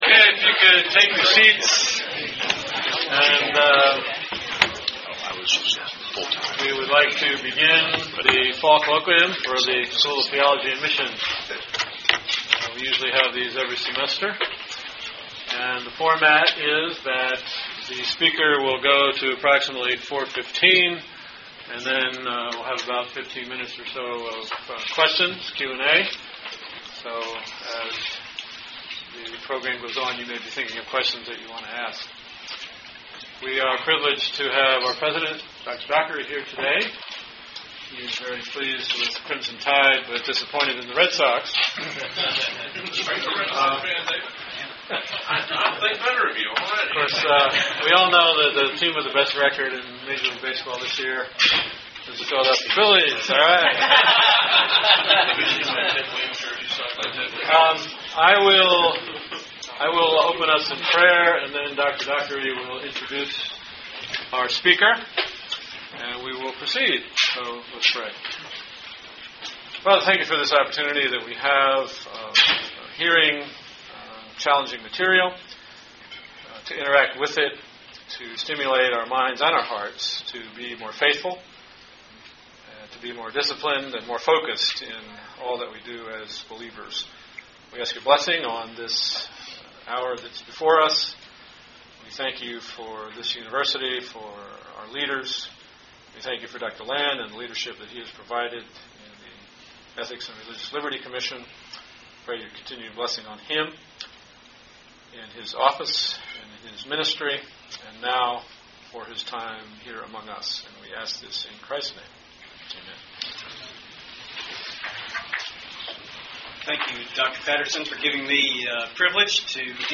School of Theology and Missions Colloquium: Richard Land